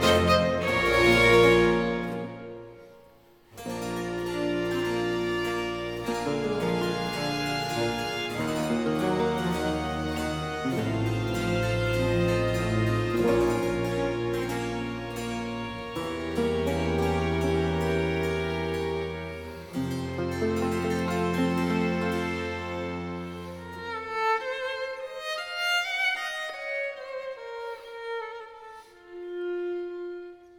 Violin concerto
si mineur